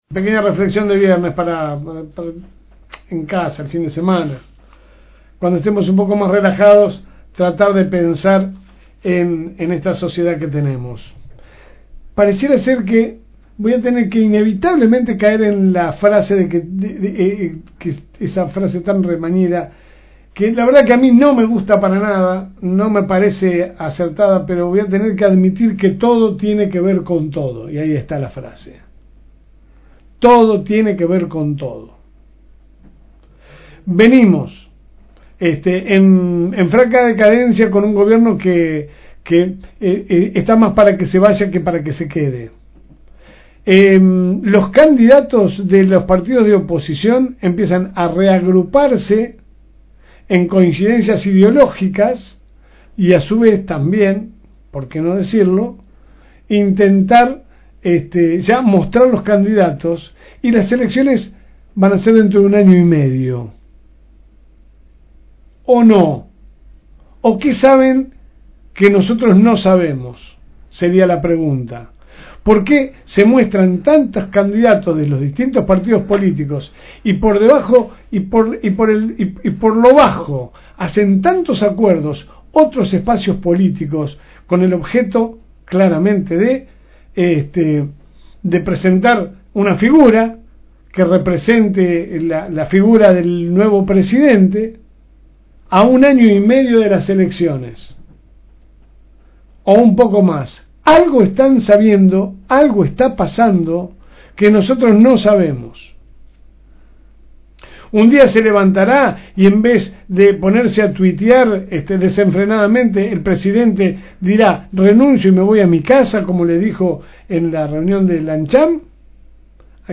Editorial LSM